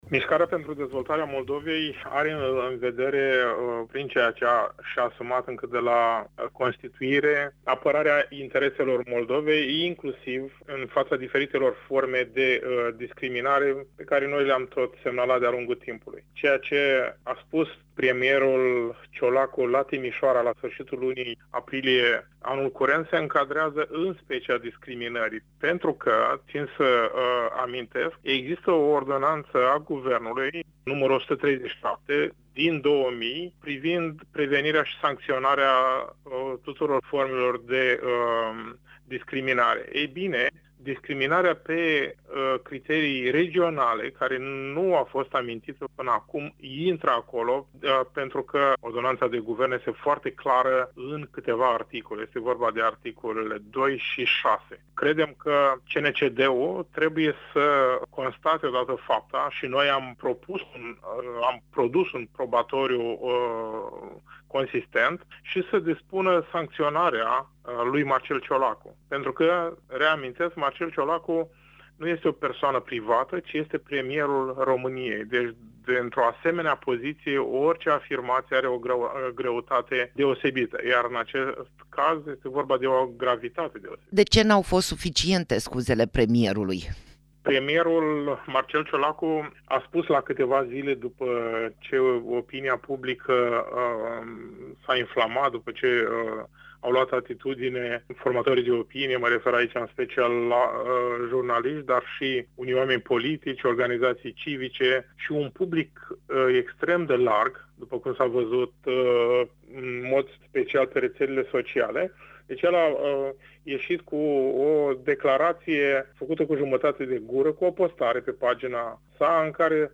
Interviu-moldoveni-CNCD-Ciolacu.mp3